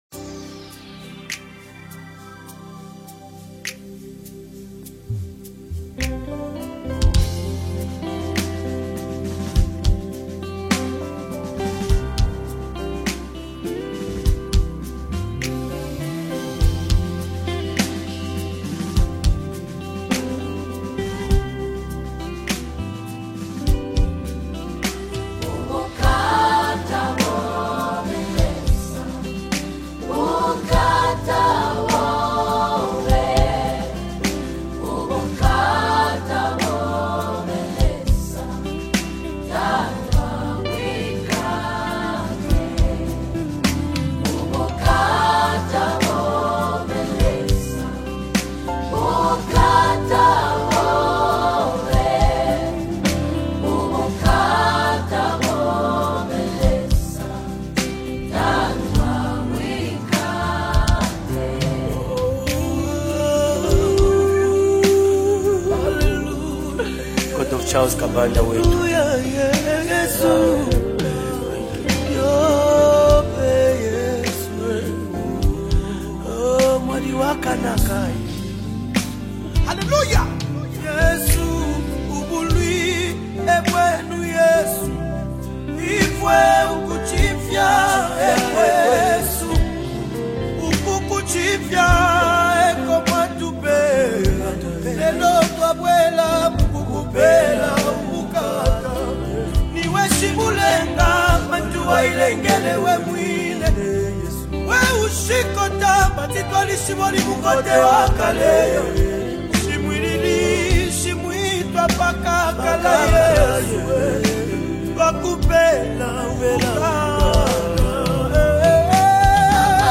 heartfelt gospel single